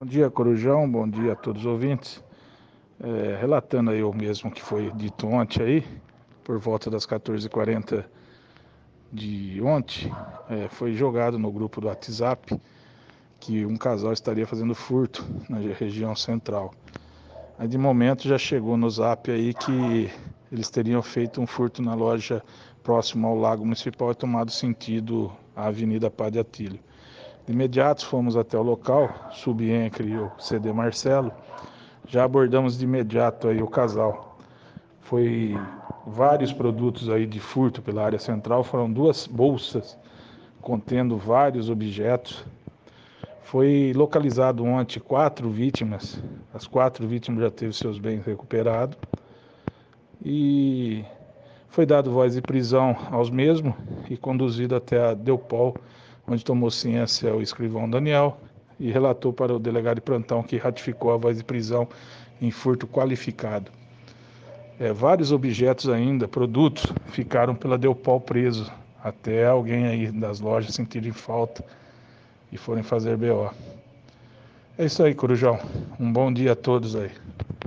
O Subinspetor